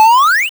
bfxr_GetItemSpecial.wav